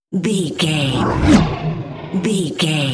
Horror whoosh creature
Sound Effects
Atonal
ominous
eerie
whoosh